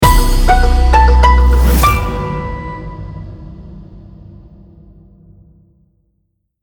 News Opener Logo
Television News Opener Logo